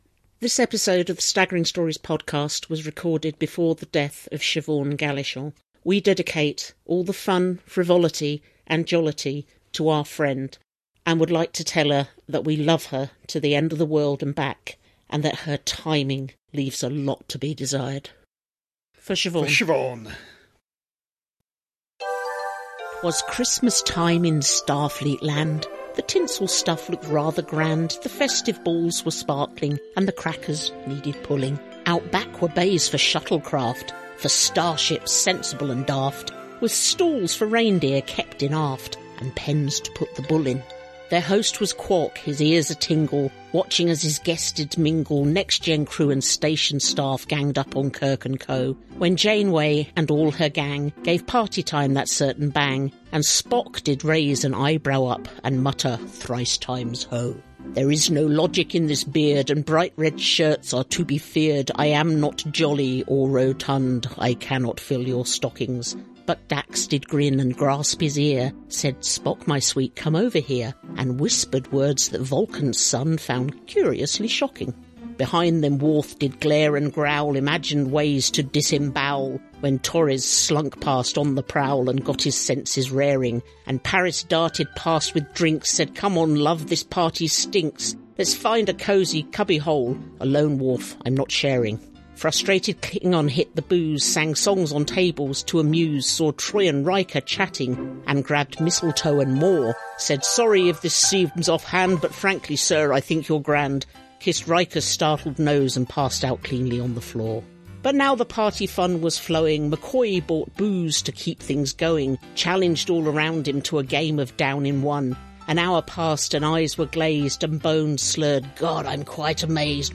00:00 – Intro and theme tune.
81:49 — End theme, disclaimer, copyright, etc.